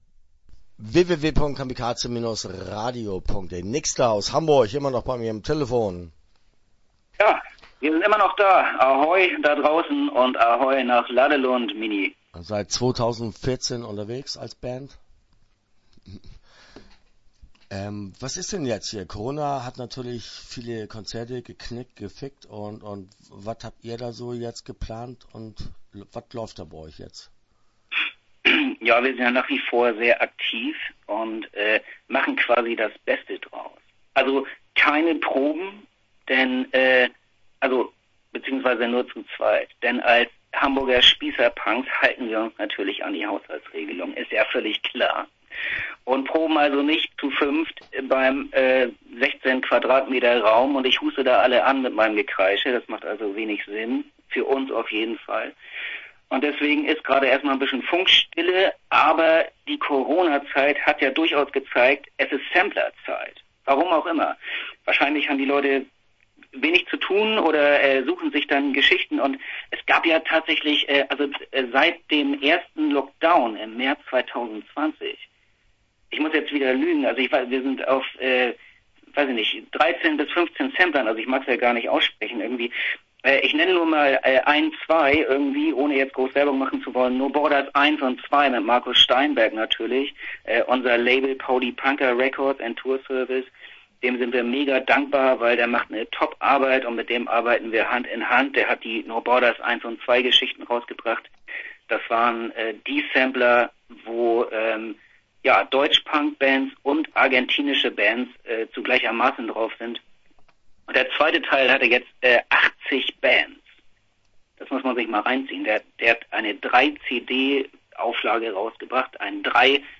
Nixda! - Interview Teil 1 (8:40)